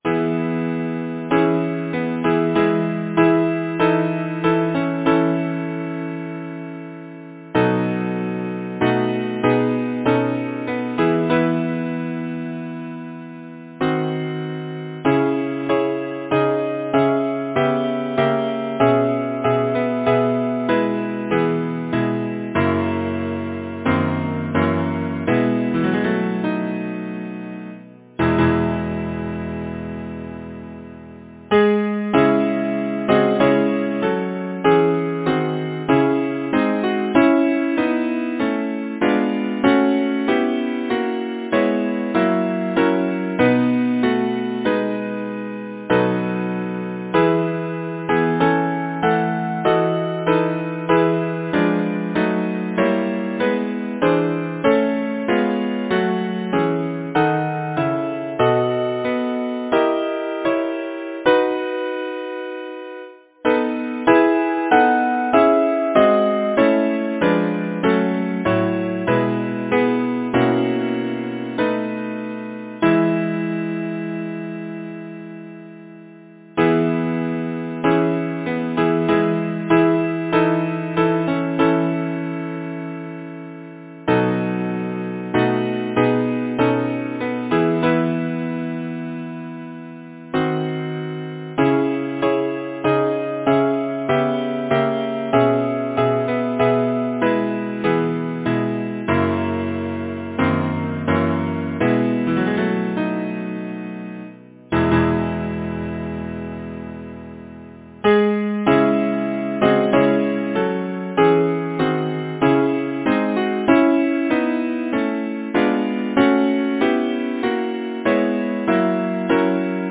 Title: The Meaning of the Song Composer: John B. Grant Lyricist: Charles Mackay Number of voices: 4vv Voicing: SATB, divisi Genre: Secular, Partsong
Language: English Instruments: A cappella